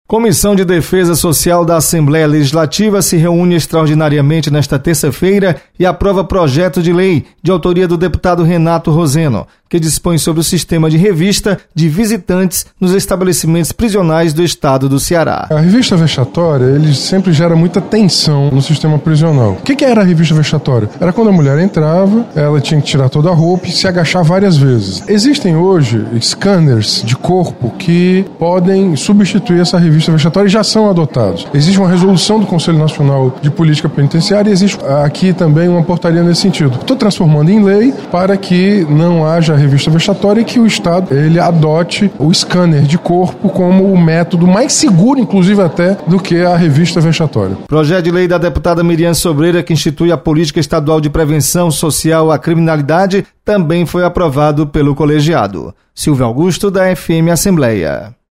Comissão de Defesa Social aprova lei sobre regulamentação de visitas nas unidades prisionais. Repórter